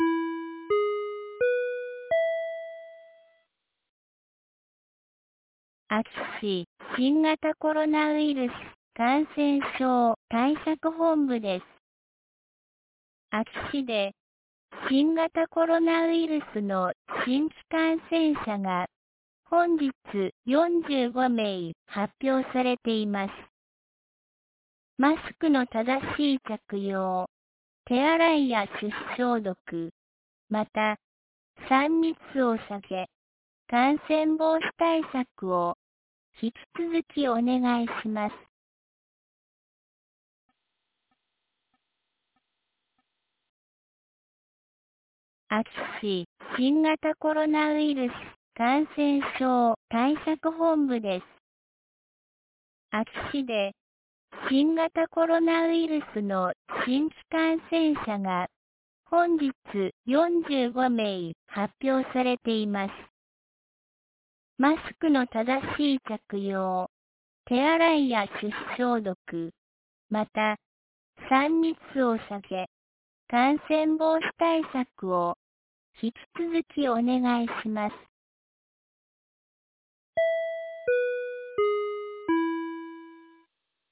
2022年08月16日 17時06分に、安芸市より全地区へ放送がありました。